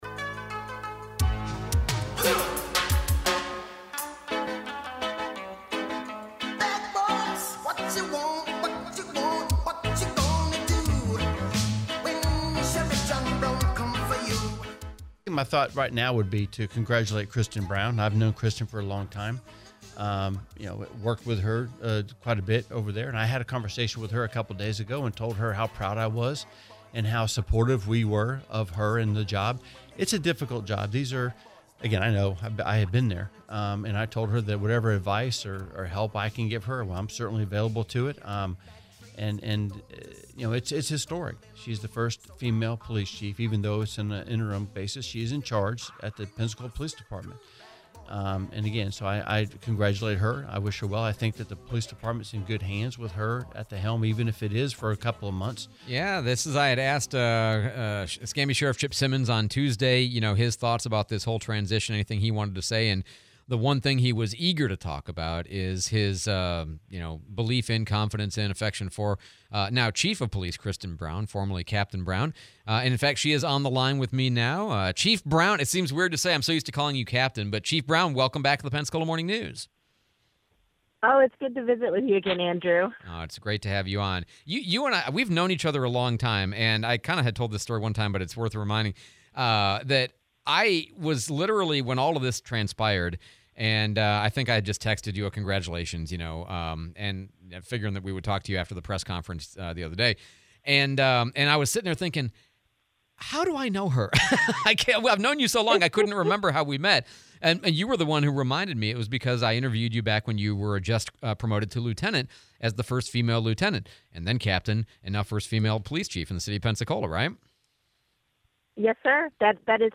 07/17/25 PPD Chief Kristin Brown interview